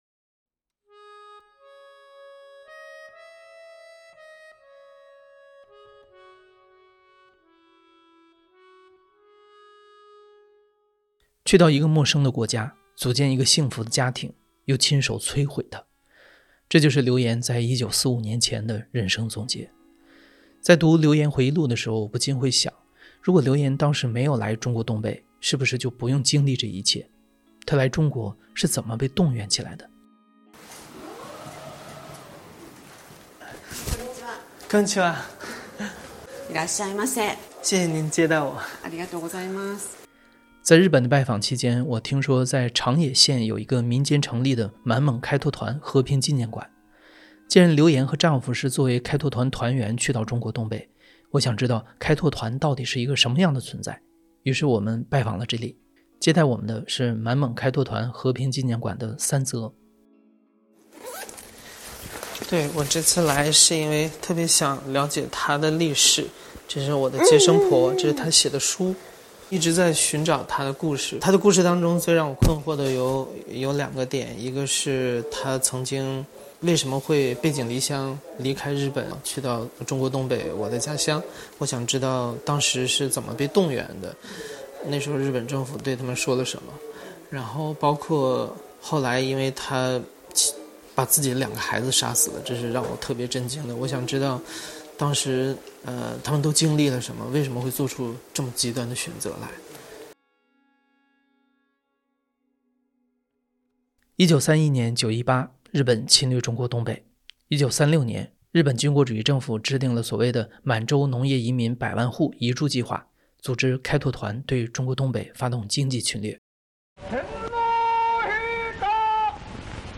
故事FM ❜ 2025年度大型系列声音纪录片 去到一个陌生的国家，组建了一个幸福的家庭，又亲手摧毁它。
故事FM 是一档亲历者自述的声音节目。